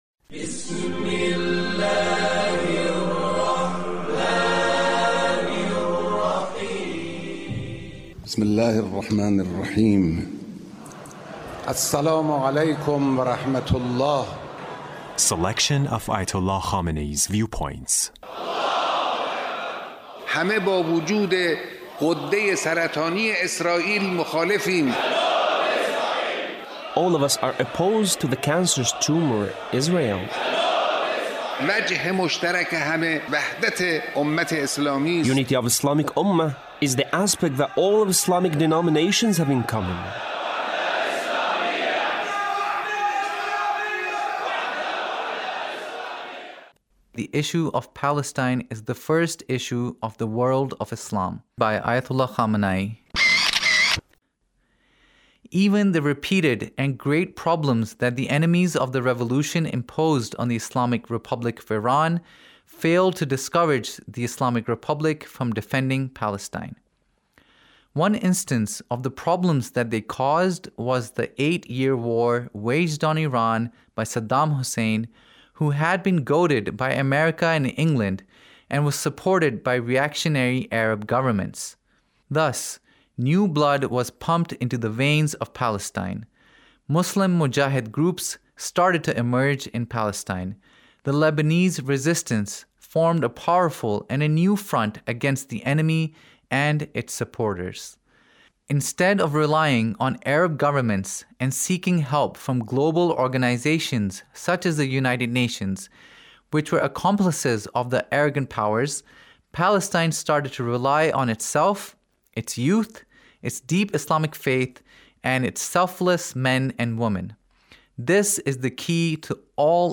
Leader's Speech about Palestine